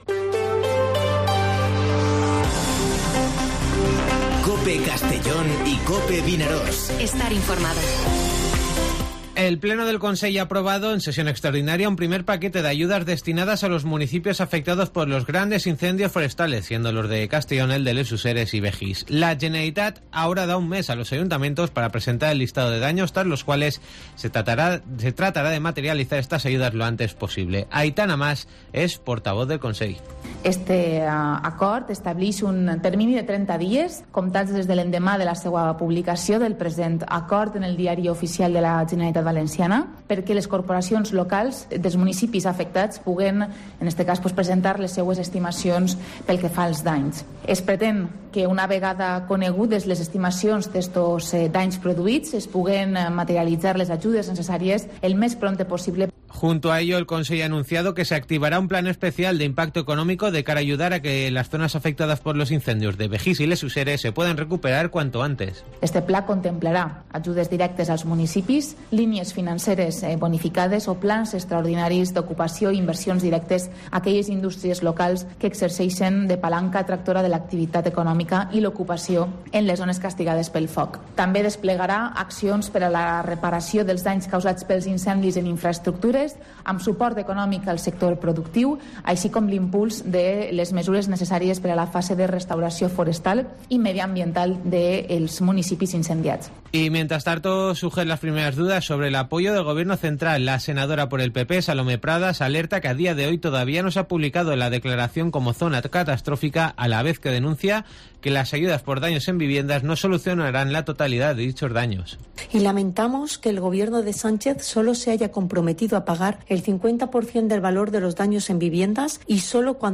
Informativo Mediodía COPE en Castellón (30/08/2022)